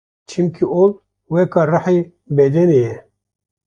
Pronounced as (IPA) /oːl/